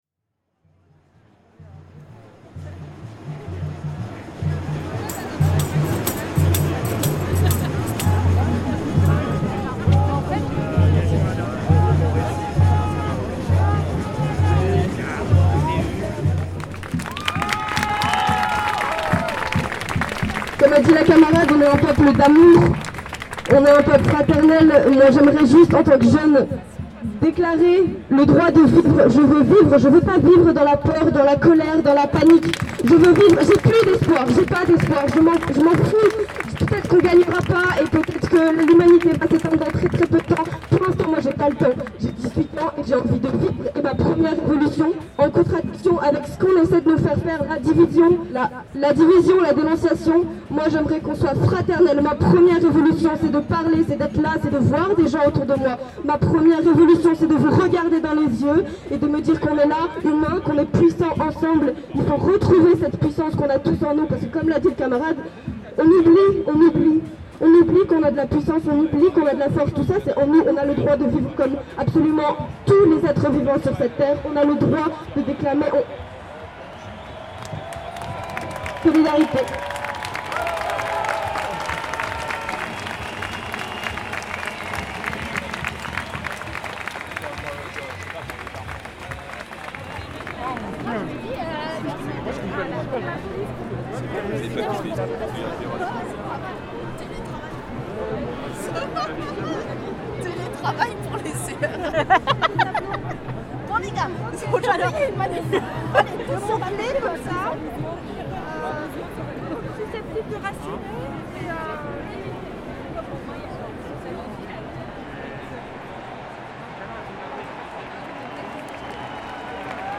Série « Ecologie acoustique d'une manifestation » (2018-)
Il s'agit de prises de sons effectuées lors de manifestations, montées mais pas retouchées.
icone son   Dérive lors de la manifestion contre la loi Sécurité, Paris, 29 novembre 2020  (8min36, 12.6Mo)